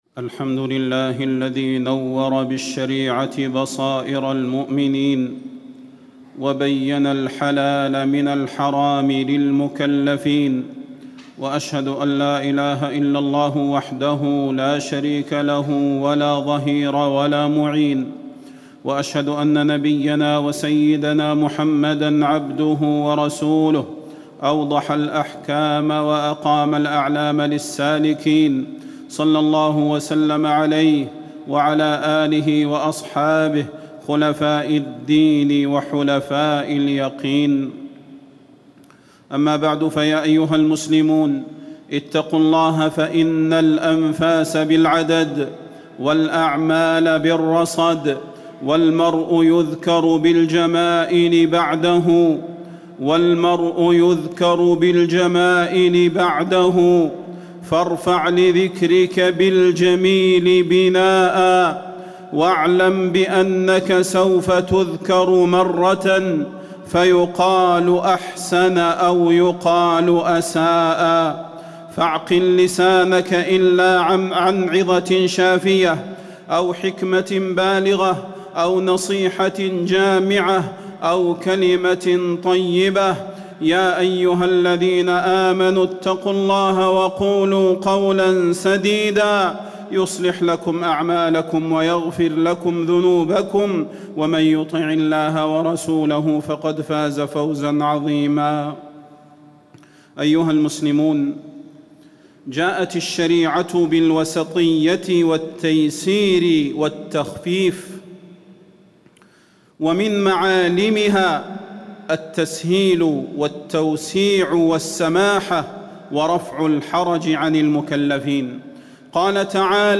تاريخ النشر ١٤ جمادى الآخرة ١٤٣٩ هـ المكان: المسجد النبوي الشيخ: فضيلة الشيخ د. صلاح بن محمد البدير فضيلة الشيخ د. صلاح بن محمد البدير تحريم الإفتاء بغير علم